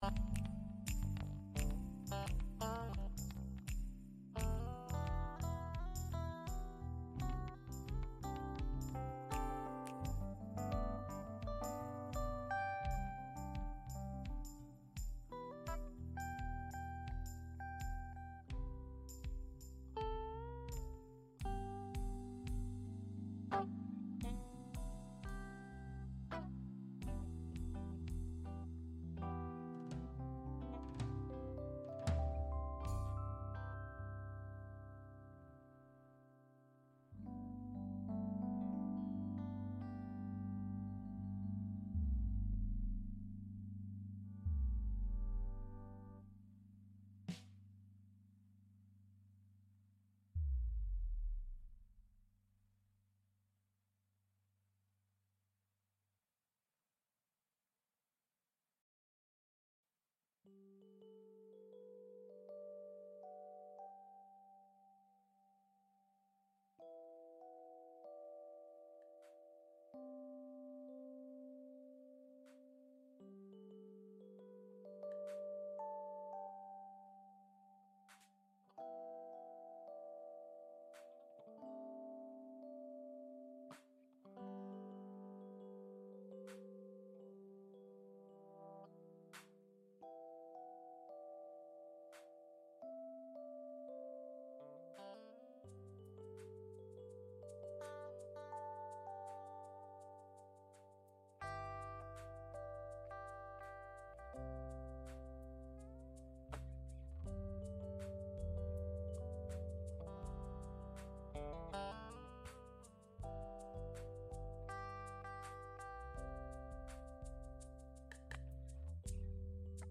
Gottesdienst am 10. März 2024 aus der Christuskirche Altona